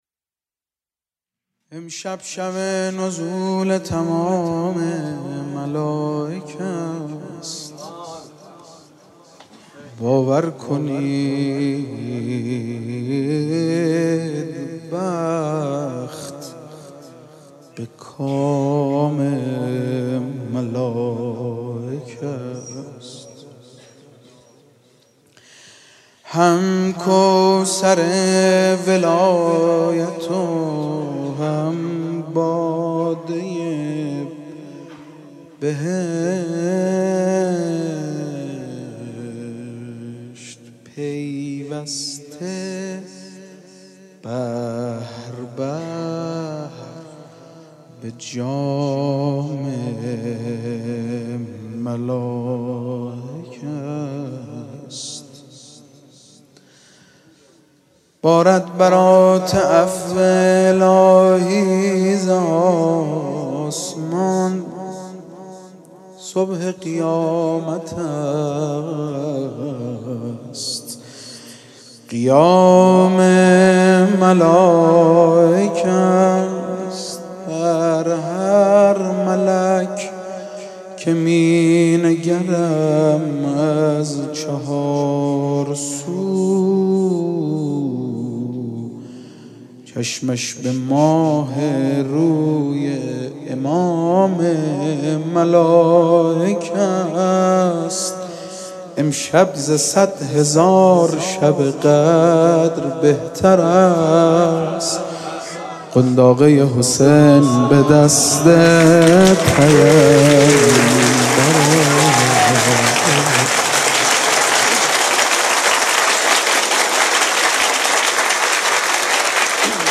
جشن میلاد امام حسین(ع)